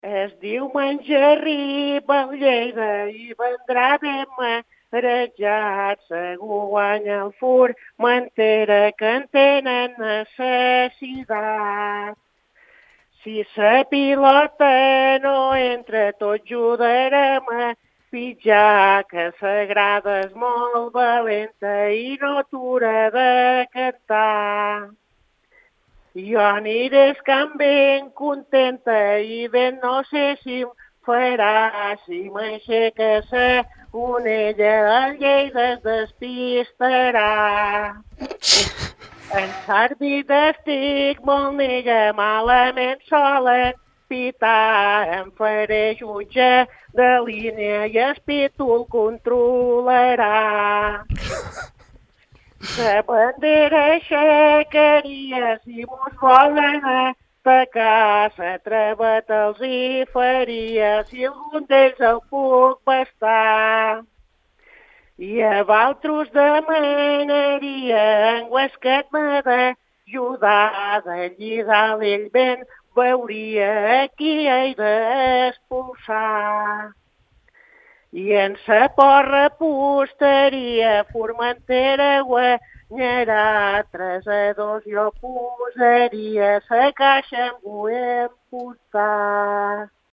Aquest matí, durant la porra que oferim cada divendres amb la inestimable col·laboració de Carbónicas Tur, hem tingut una cridada molt especial.